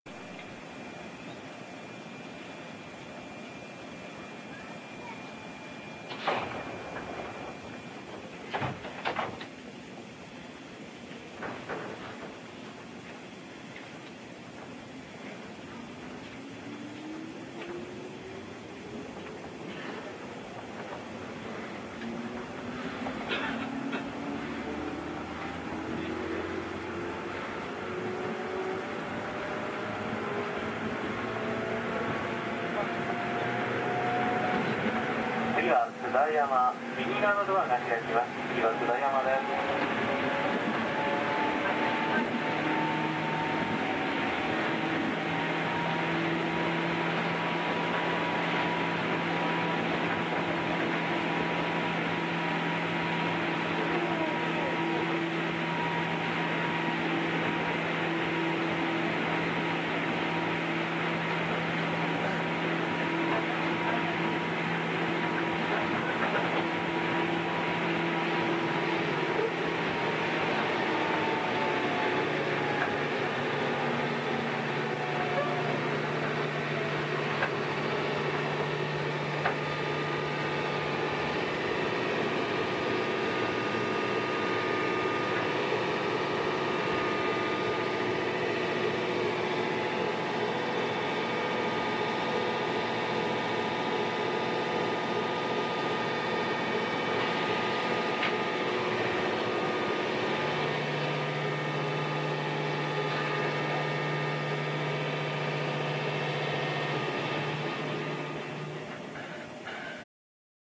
同じ車両ですが少々川崎寄りの区間です。